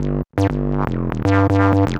TSNRG2 Bassline 012.wav